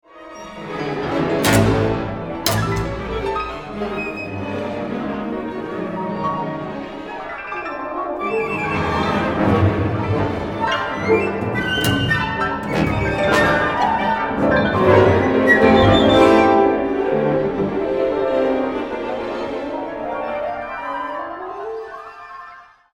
for hyperpiano and orchestra